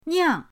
niang4.mp3